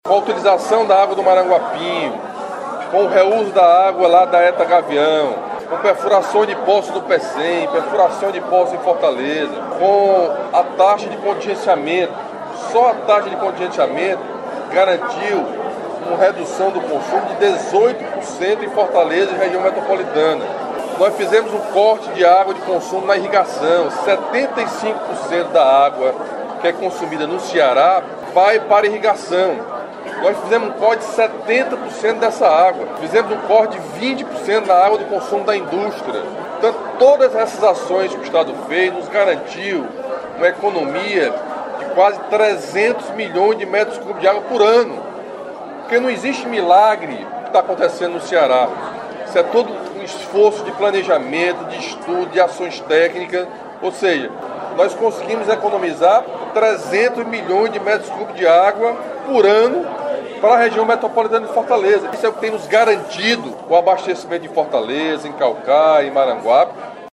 Local: Palácio da Abolição
Entrevistas:
Governador Camilo Santana